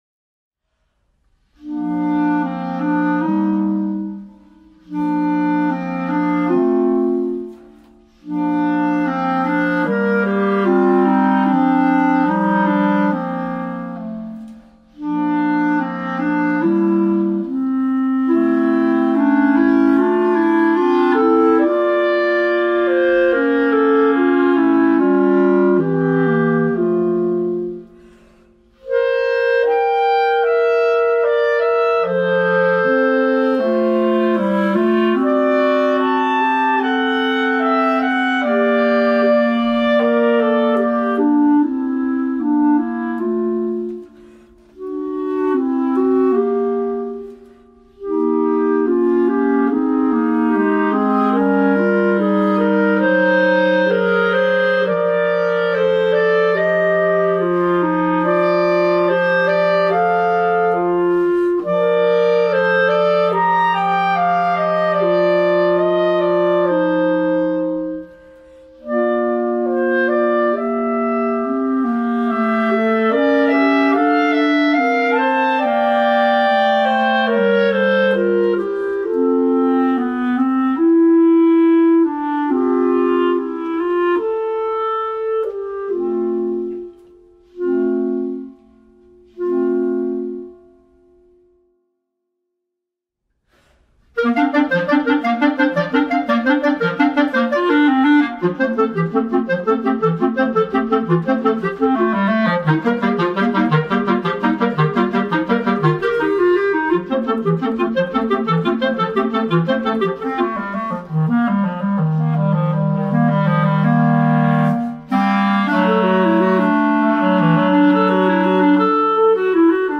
B♭ Clarinet 1 B♭ Clarinet 2 B♭ Clarinet 3
单簧管三重奏
这是一首由两首小曲组成的单簧管三重奏。第一首是能让人舒缓感受单簧管温暖音色的乐曲。
第二首允许在68-108的速度范围内自由设定，请根据演奏者水平调整速度。